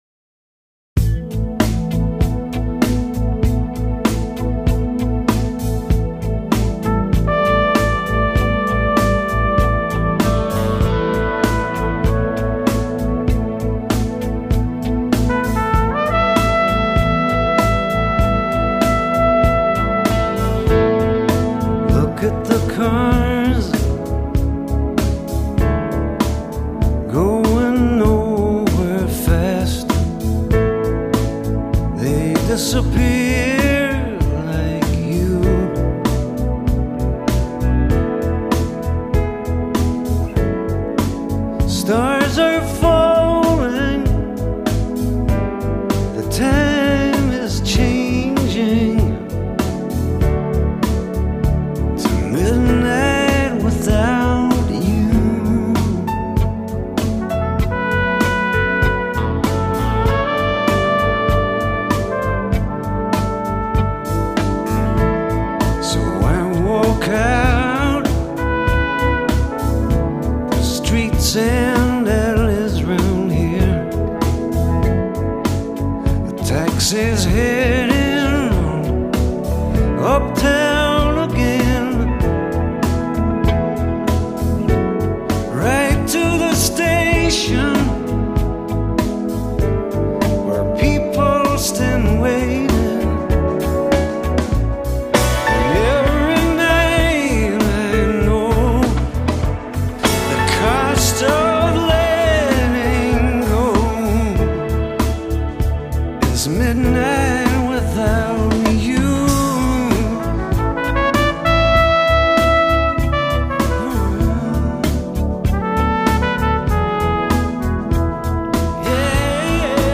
爵士小号手
小号演奏